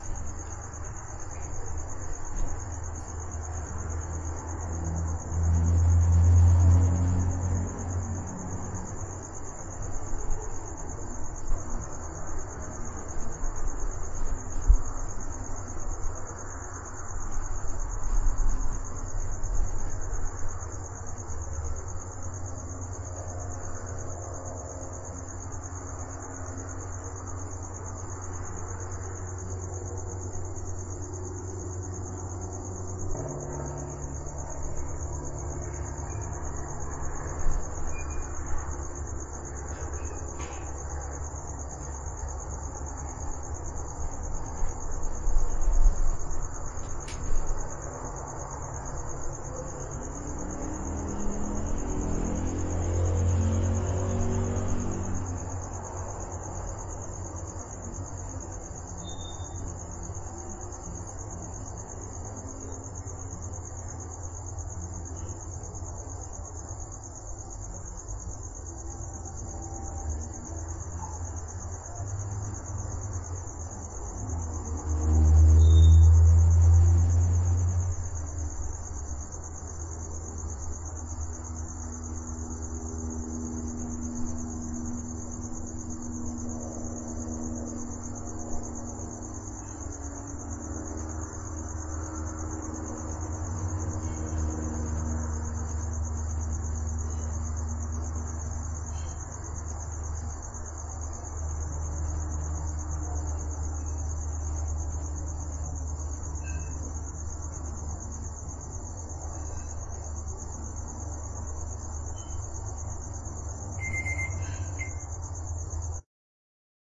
白天的室外环境
描述：用于户外氛围噪音。
Tag: 背景音 音景 环境 背景 一般的噪声 大气